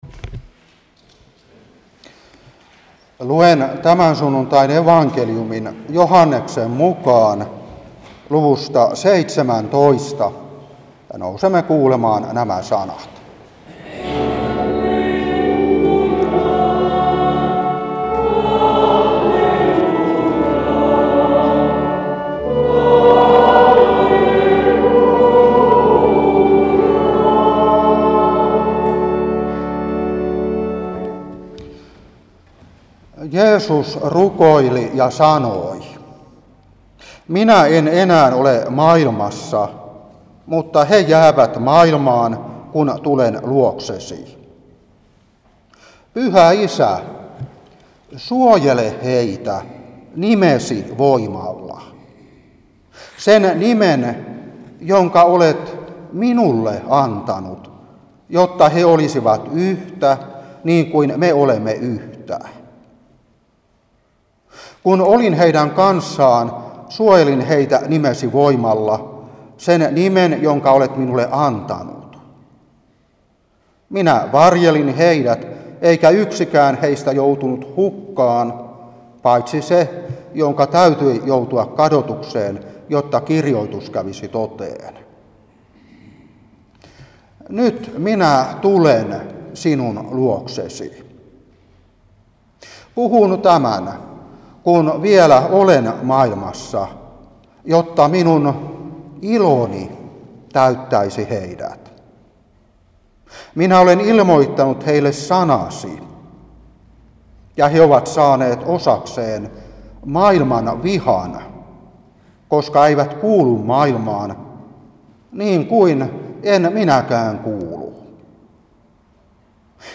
Saarna 2023-4.